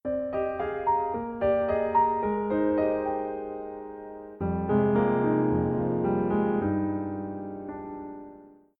failsound.ogg